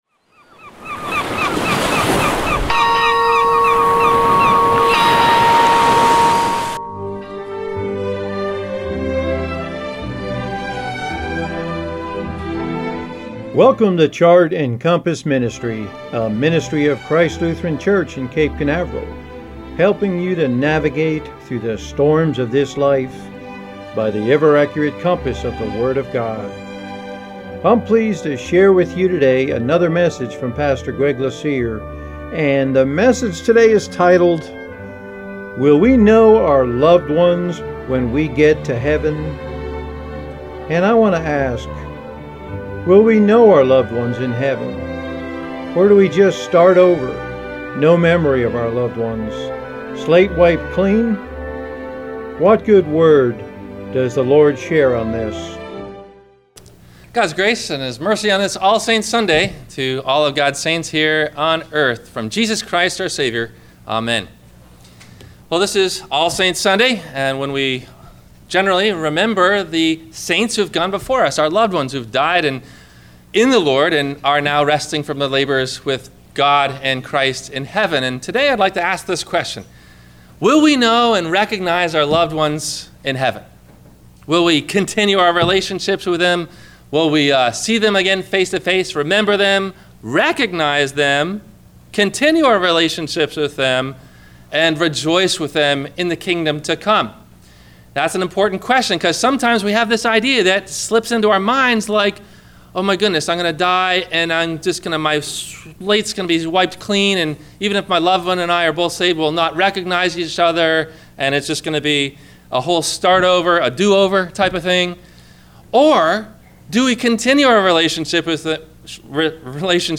How do Old Testament Prophecies Prove that Jesus is the Christ? – WMIE Radio Sermon – December 28 2015